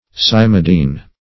cymidine - definition of cymidine - synonyms, pronunciation, spelling from Free Dictionary